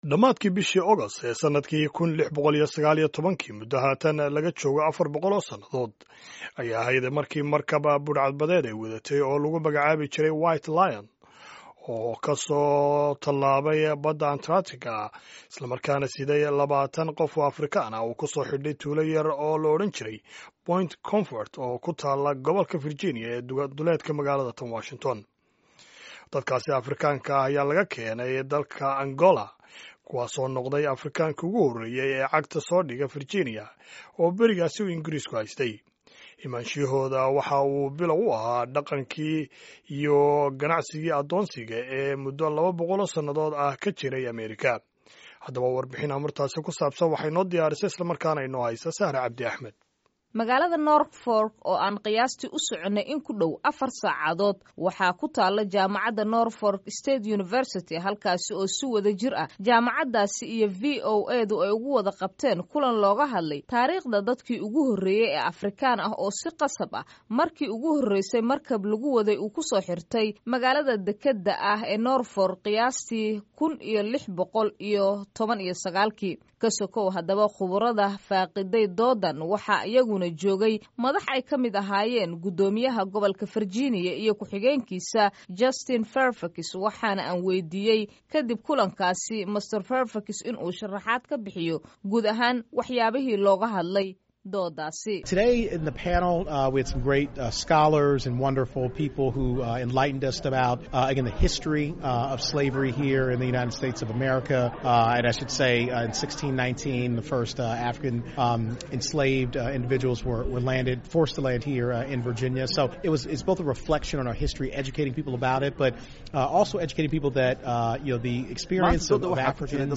DHAGEYSO WARBIXINTA OO DHAN